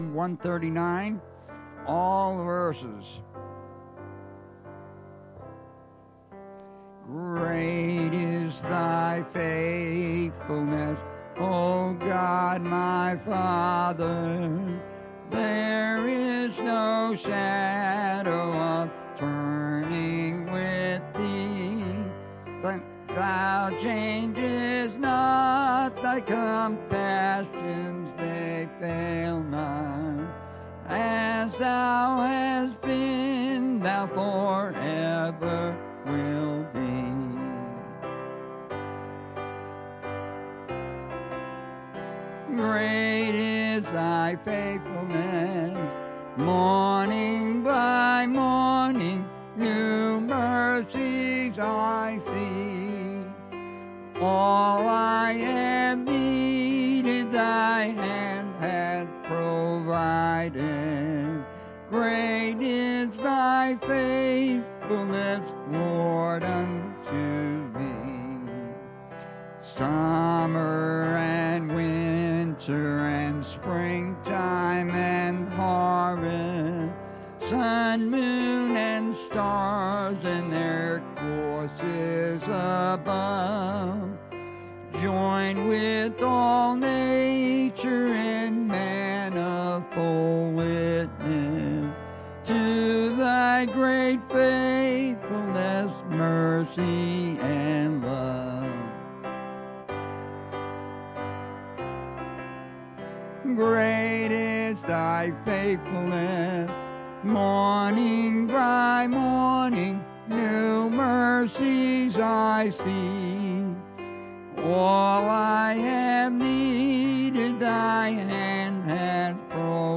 Bible Text: Revelation 10:1-11 | Preacher: